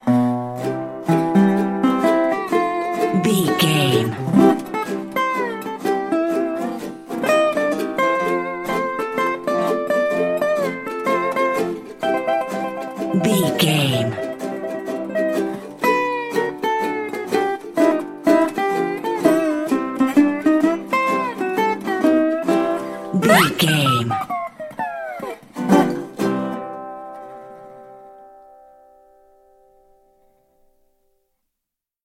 Ionian/Major
acoustic guitar
percussion
ukulele
slack key guitar